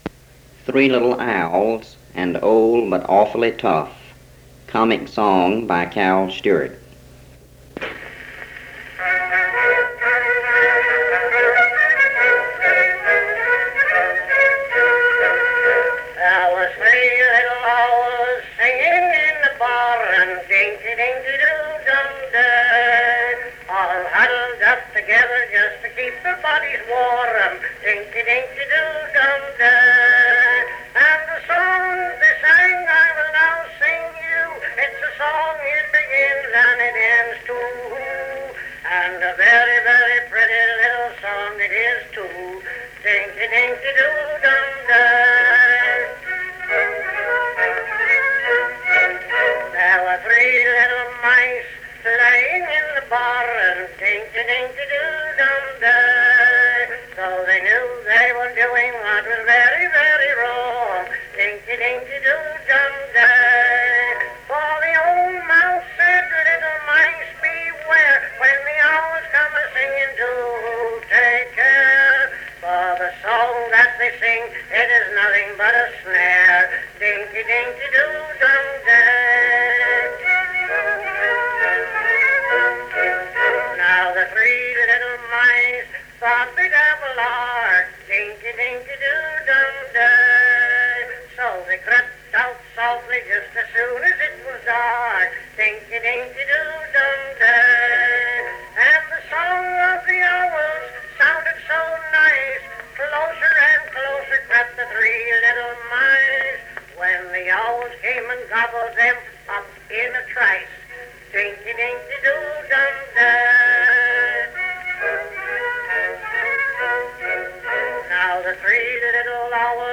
Music (performing arts genre)